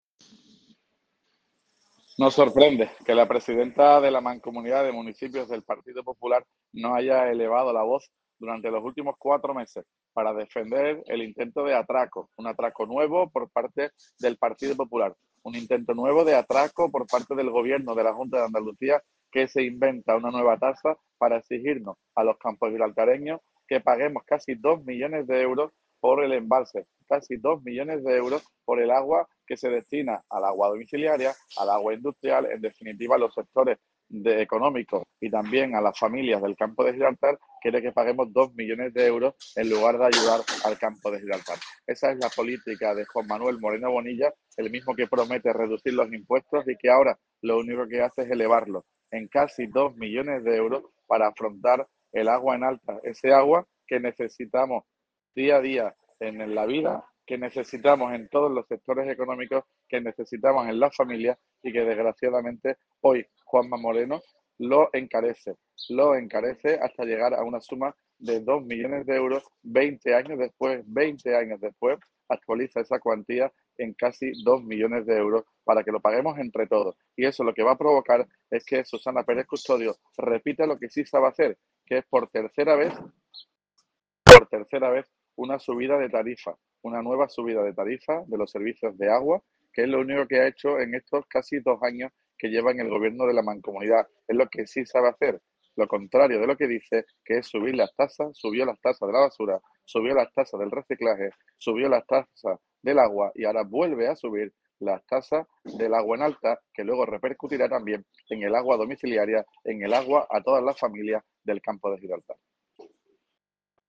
Audio alcalde tasa embalse.mp3